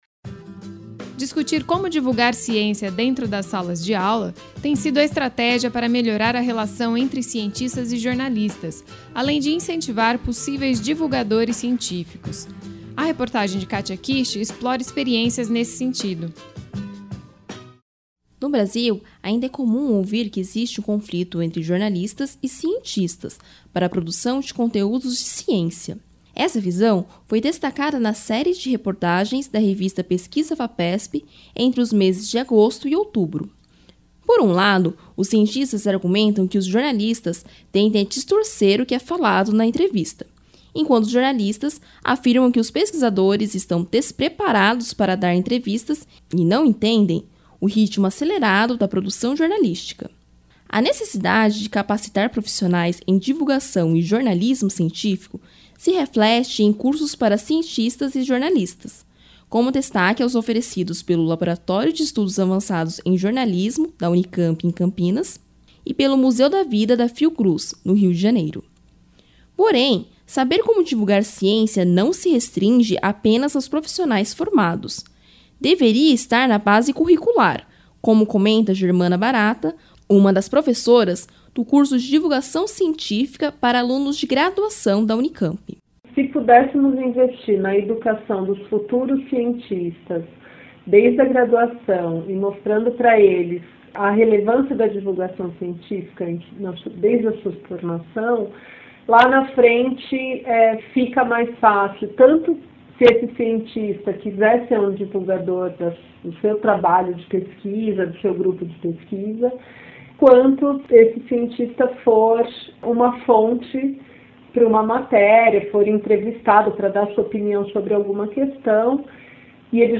Reportagem
14-03-reportagem-ciencia.mp3